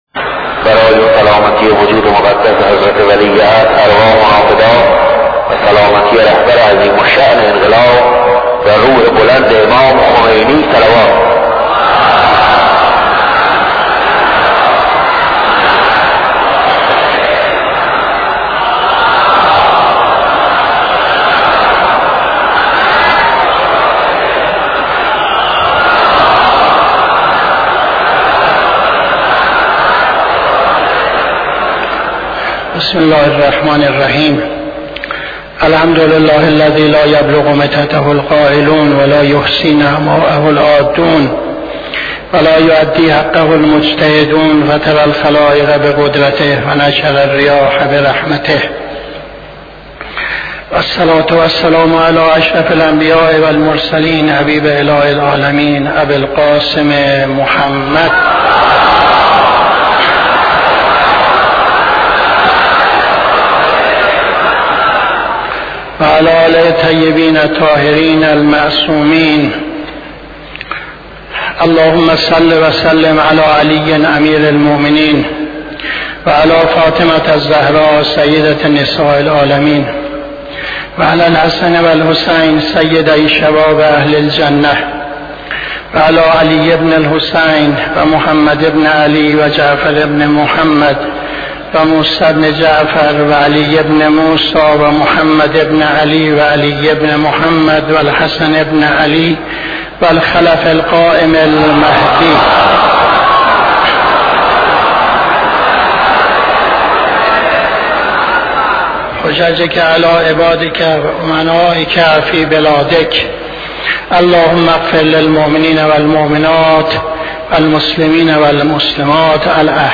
خطبه دوم نماز جمعه 14-12-77